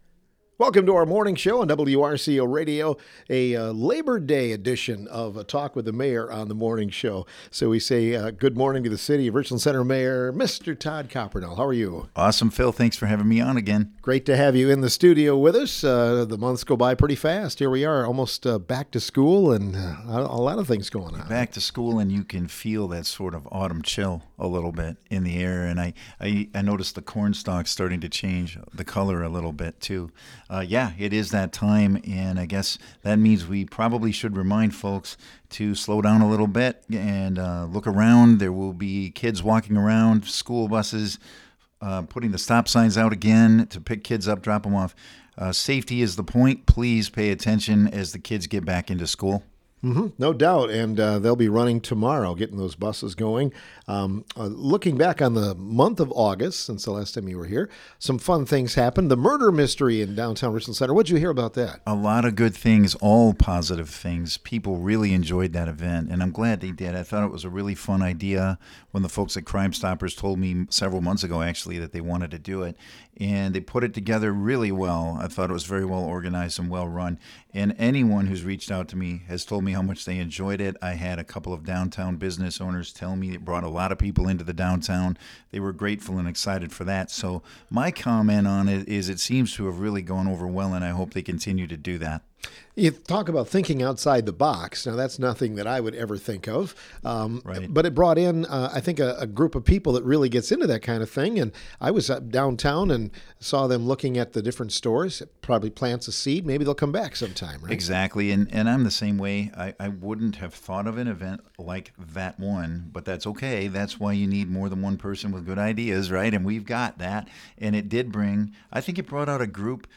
The Morning Show started off the month of September with a visit from Richland Center Mayor, Todd Coppernoll, who provided a community update.